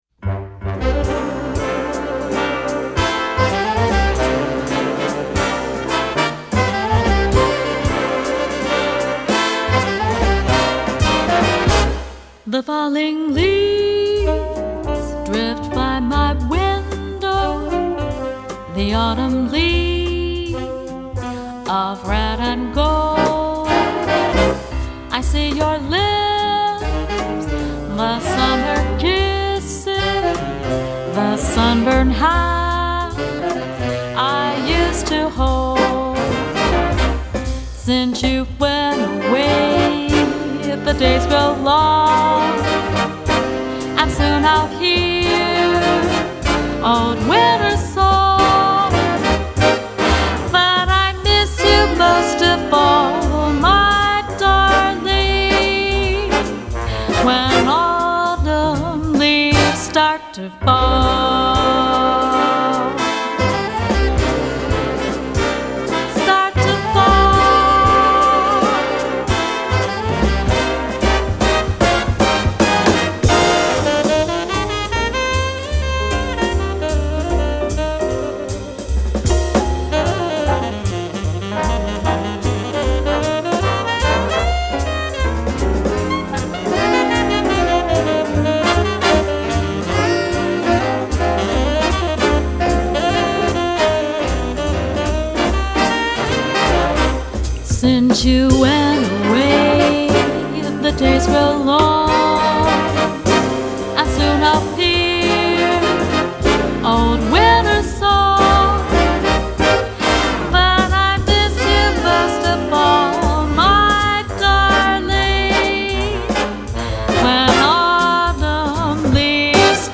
Key: C minor
Voicing: Jazz Ensemble w/Vocal